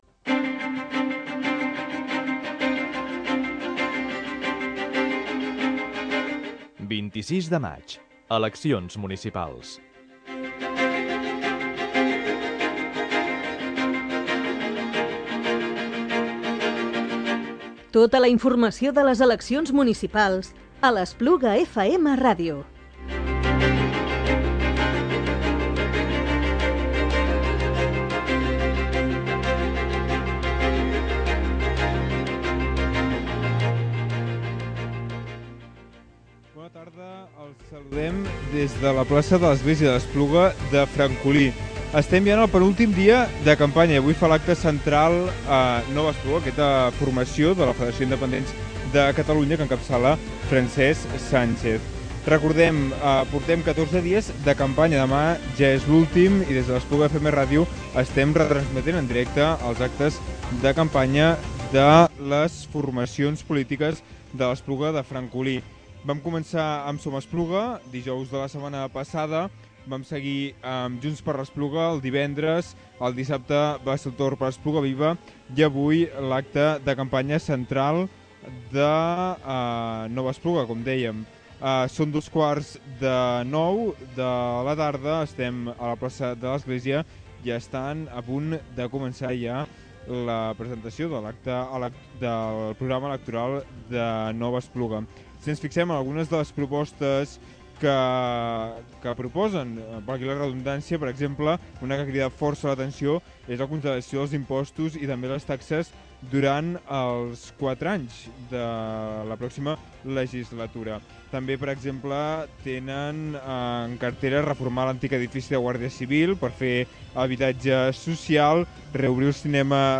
Acte electoral de Nova Espluga del dijous 23 de maig del 2019 – Eleccions Municipals
Nova Espluga ha presentat aquest dijous el seu programa electoral a la plaça de l’Església.
Acte-electoral-Nova-Espluga.mp3